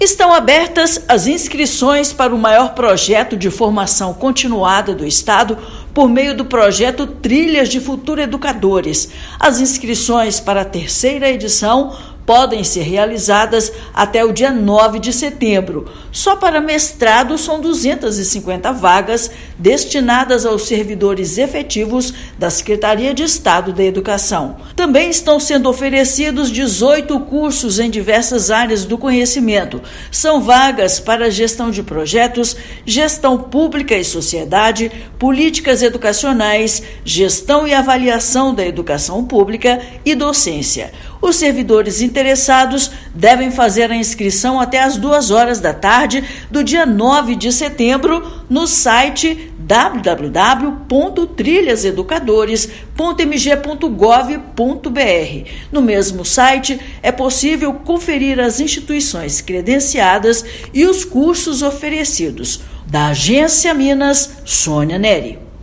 Projeto oferece cursos de mestrado em diversas áreas; inscrições vão até 9/9. Ouça matéria de rádio.